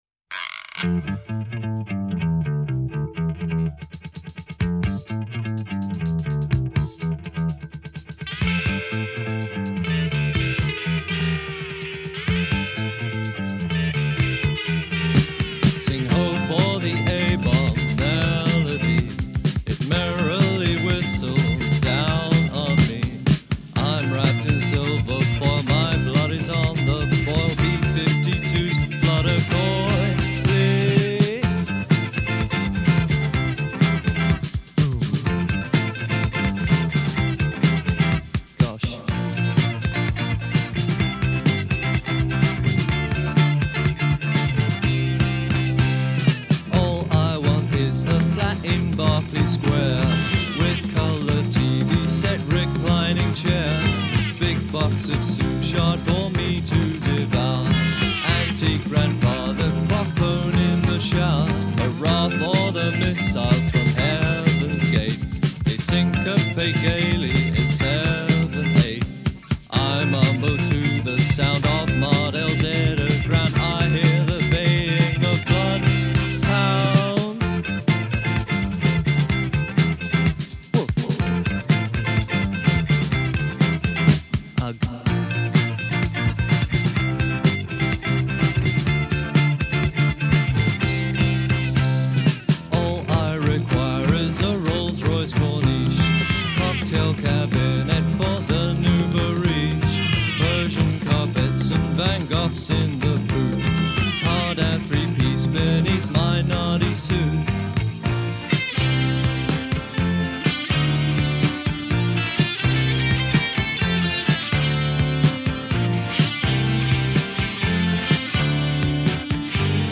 mildly funky, cheeky pop song